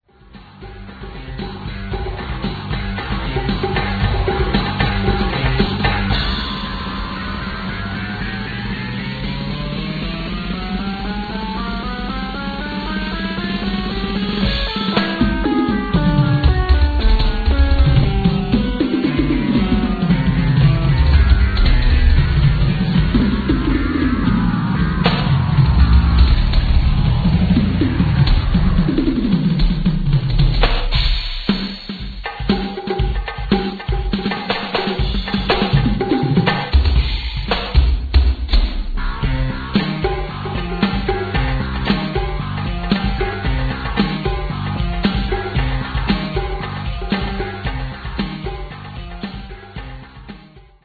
a drum set based song
with bass and guitar accompaniment.